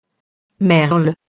merle [mɛrl] nom masculin (bas lat. merulus, class. merula)